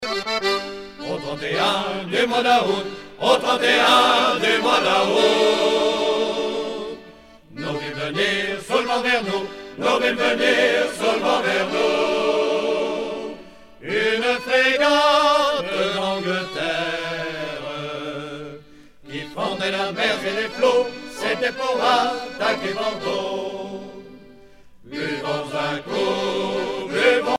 Chants de marins
Pièce musicale éditée